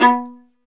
Index of /90_sSampleCDs/Roland - String Master Series/STR_Viola Solo/STR_Vla3 _ marc